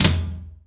ROOM TOM4B.wav